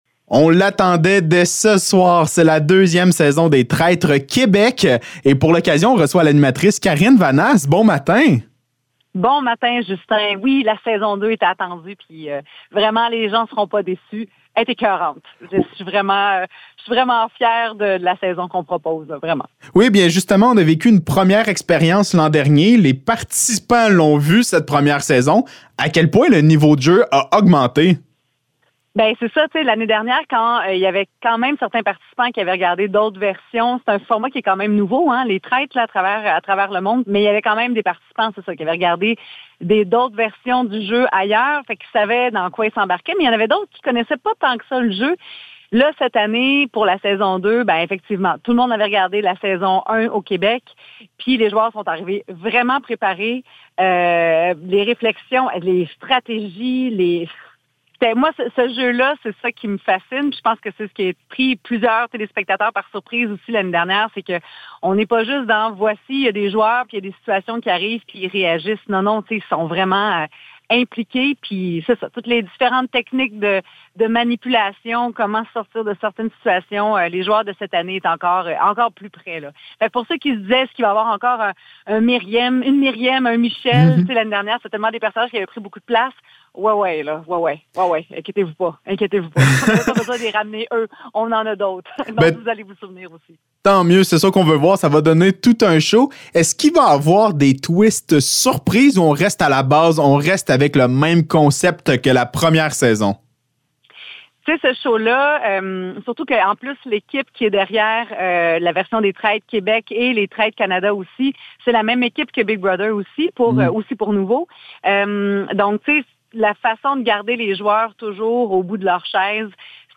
Entrevue avec Karine Vanasse
ENTREVUE-KARINE-VANASSE-LES-TRAITRES-WEB.mp3